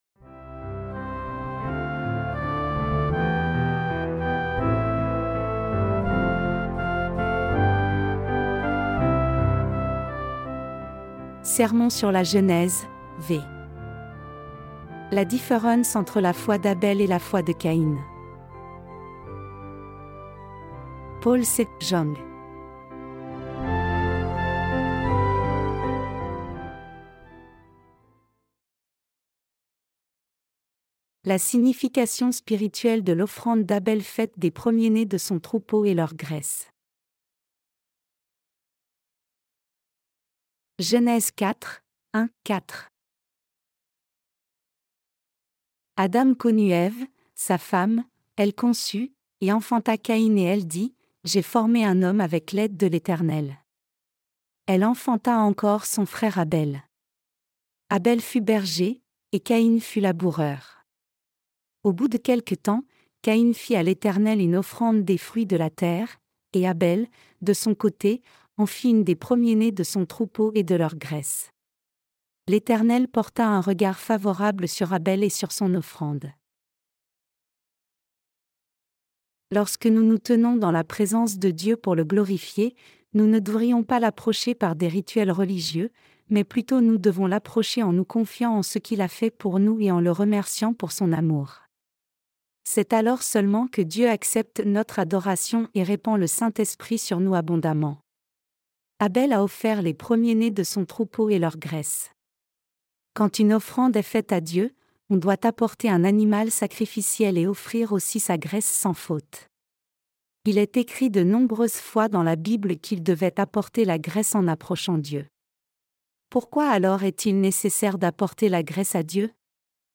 Sermons sur la Genèse (V) - LA DIFFERENCE ENTRE LA FOI D’ABEL ET LA FOI DE CAÏN 1.